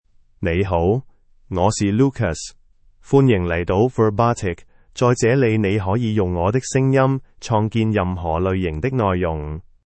MaleChinese (Cantonese, Hong Kong)
LucasMale Chinese AI voice
Lucas is a male AI voice for Chinese (Cantonese, Hong Kong).
Voice sample
Male